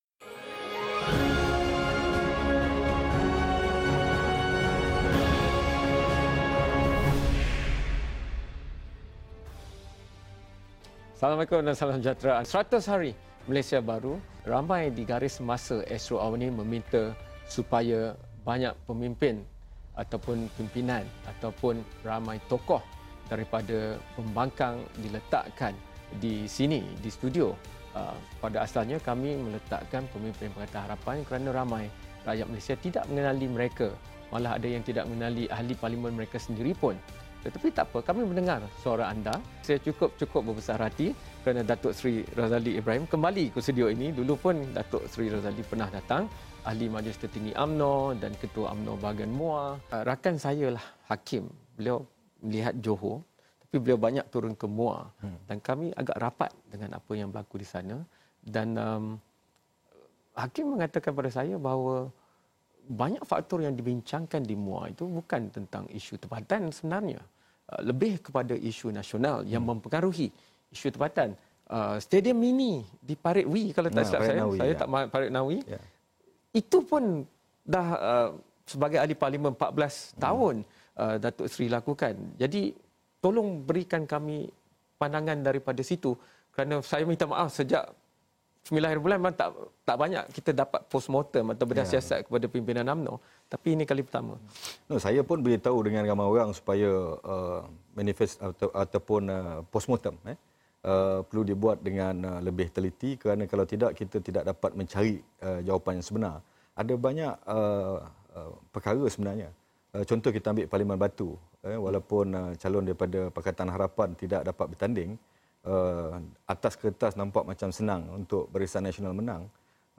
Diskusi pasca PRU14